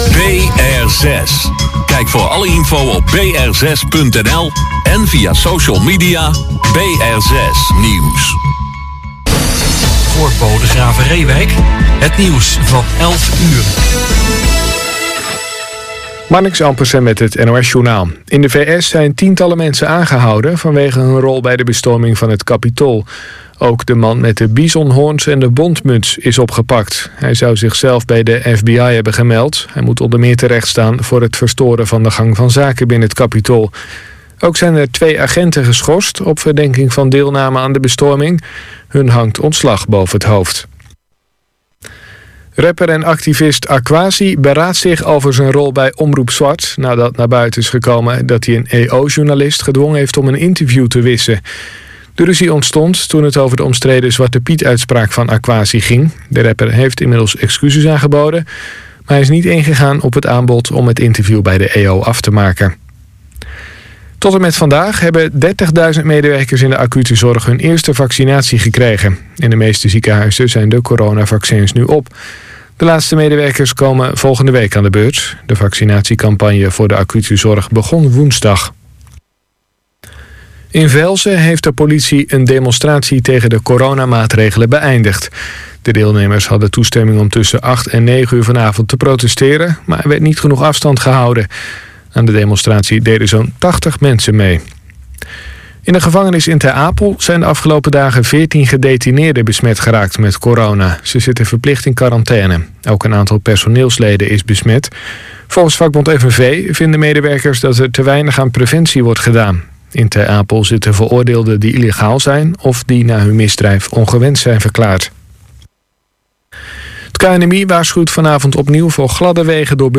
Tenor-sax
Piano
Drums
Van Gelder Studio Englewood Cliffs, New Jersey